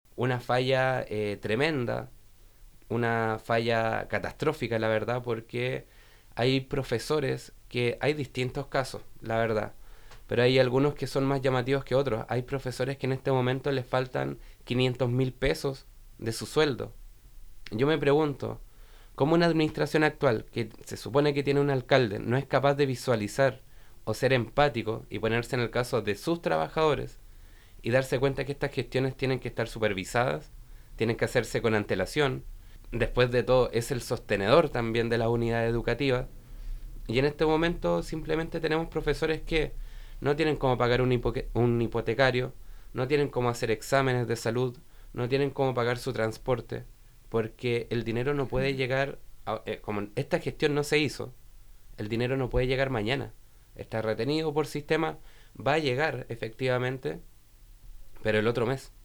El concejal señaló que esto es una clara falla en la administración, ya que no se visualizó la problemática que están viviendo los trabajadores, quienes han visto su sueldo con hasta $500.000 menos.